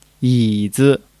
yi3--zi.mp3